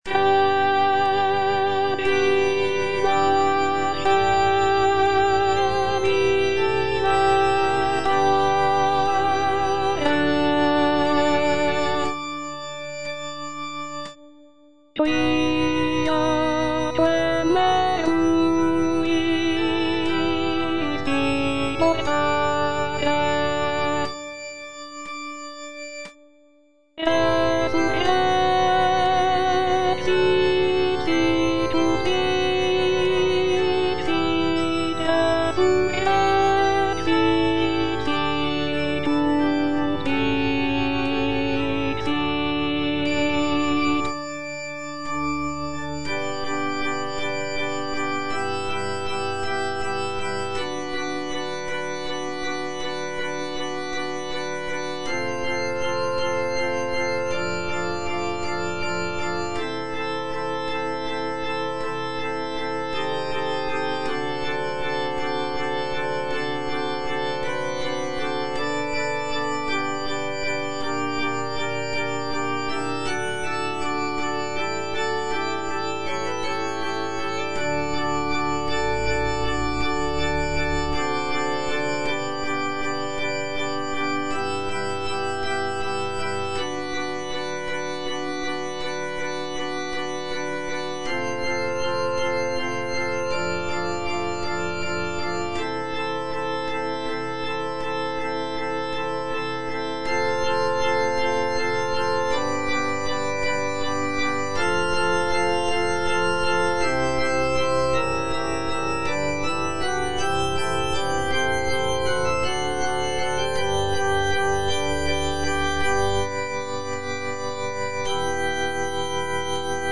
P. MASCAGNI - REGINA COELI DA "CAVALLERIA RUSTICANA" Internal choir, alto (Voice with metronome) Ads stop: